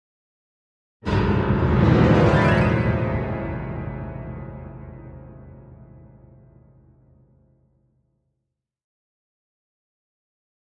Piano Dramatic Rising Play